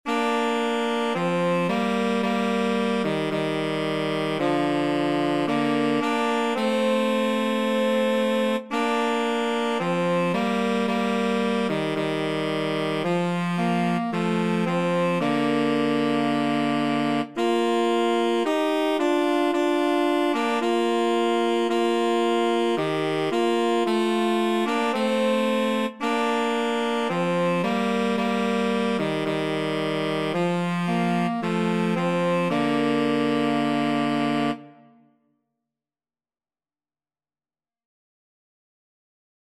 Tenor Sax Duet  (View more Easy Tenor Sax Duet Music)
Classical (View more Classical Tenor Sax Duet Music)